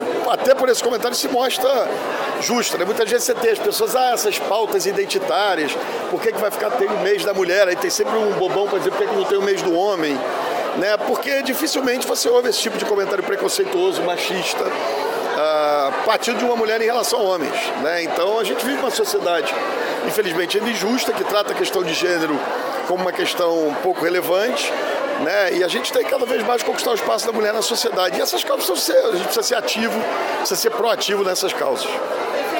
Durante evento pelo Dia internacional da Mulher, no Museu de Artes do Rio, na Zona Portuária, o prefeito Eduardo Paes afirmou que o fim da exigência do passaporte de vacinação vai depender do Avanço da dose de reforço.